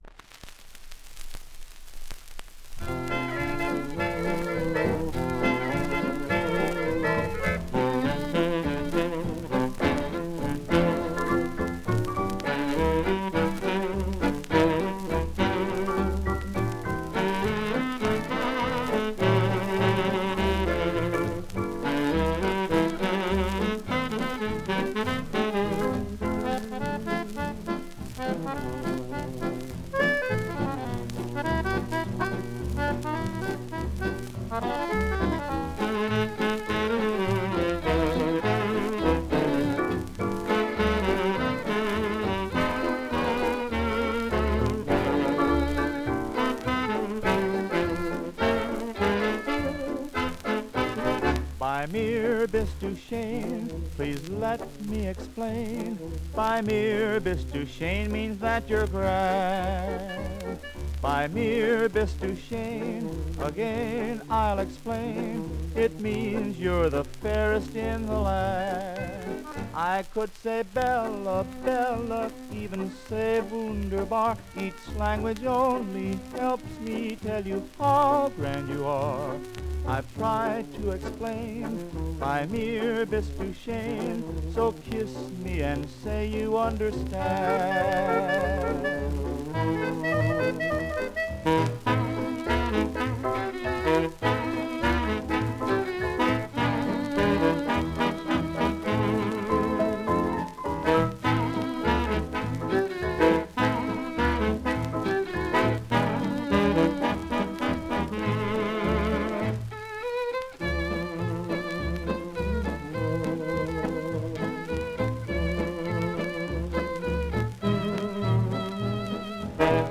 Genre: Popular Music.